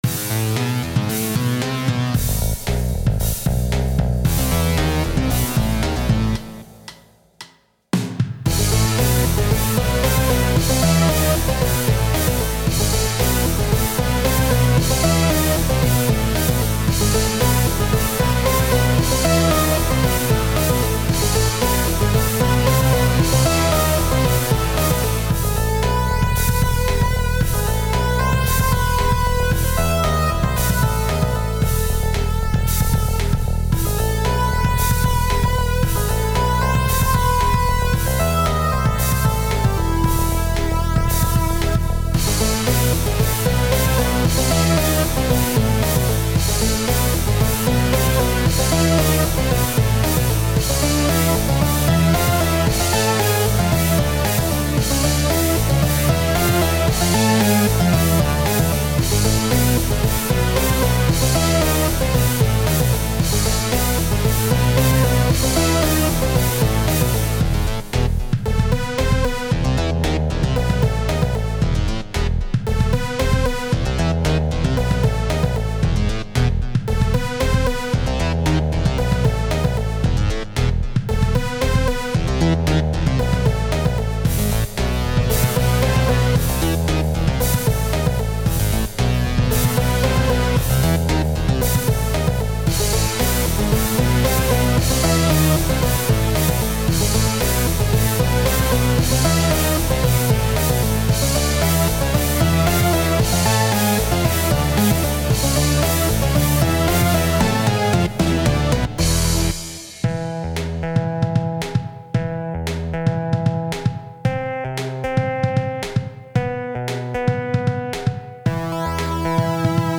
Как обычно это фантазия на тему Battletech. Основная партия придумана на бас гитаре, включая соло. На ваш суд инструментальная "песня" (состоит из двух частей).